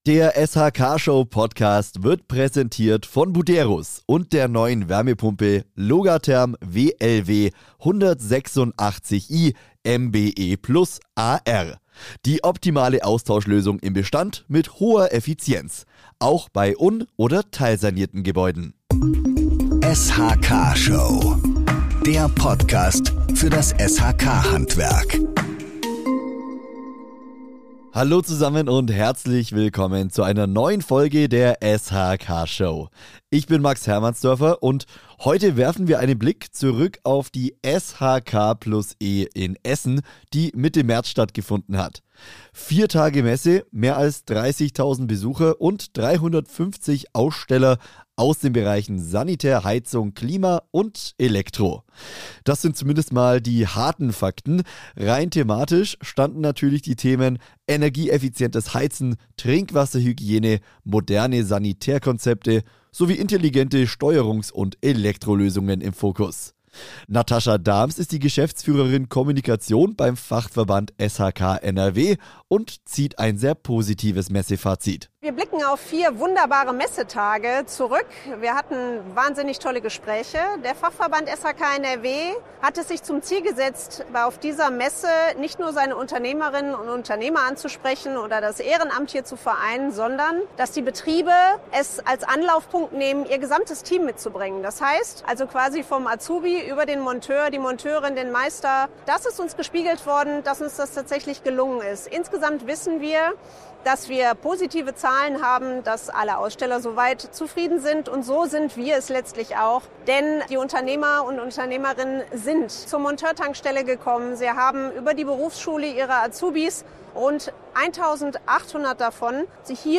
In dieser Folge der SHK-Show nehmen wir euch mit zur Verleihung des „Best of SHK Award 2026“, die am 19. März auf der SHK+E in Essen – direkt am Stand von Buderus – über die Bühne ging.